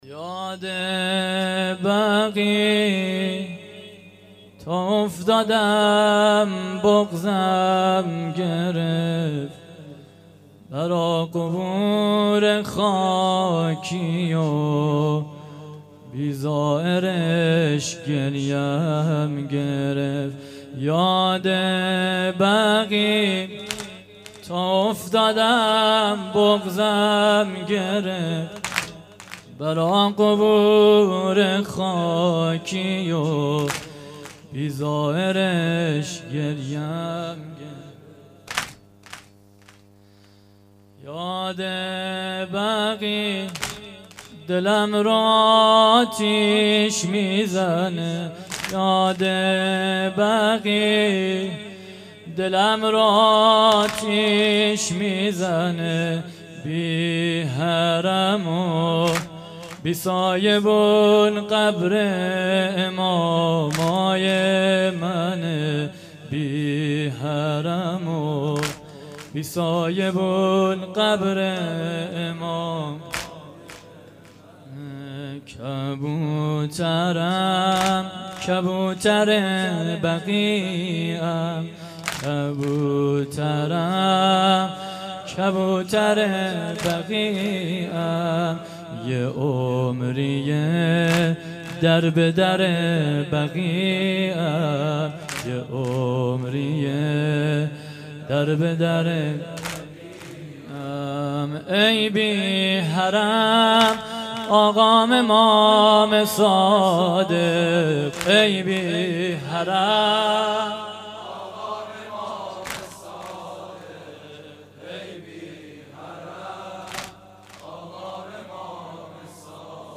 :: گزارش صوتی برنامه شهادت امام صادق علیه السلام 1437 هـ.ق - 1395 هـ.ش ::